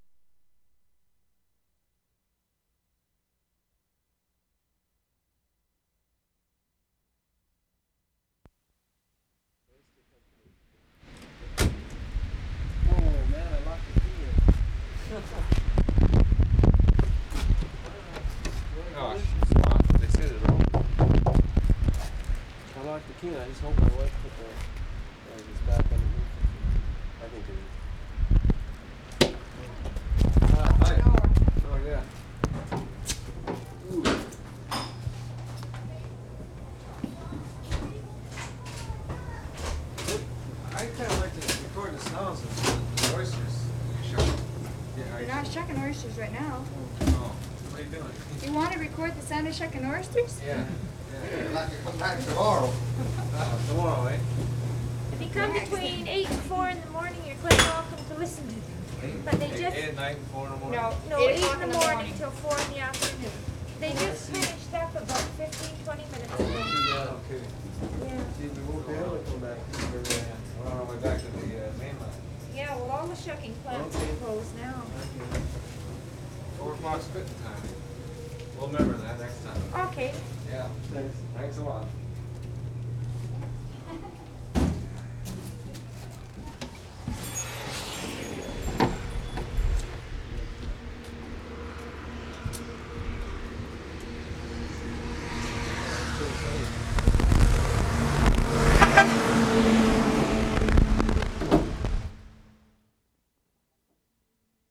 WORLD SOUNDSCAPE PROJECT TAPE LIBRARY
OYSTER SHUCKING PLANT 1'30"
11. Bad wind and confusion ("locked the key in") at beginning. Walking inside and asking to record oyster shucking, without success.